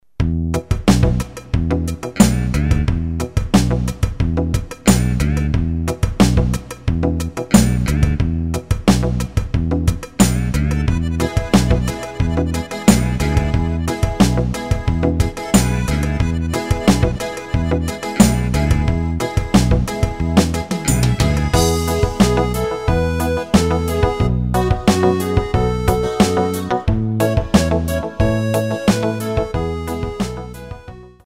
Tempo: 90 BPM.
MP3 with melody DEMO 30s (0.5 MB)zdarma